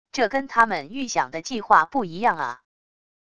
这跟他们预想的计划不一样啊wav音频生成系统WAV Audio Player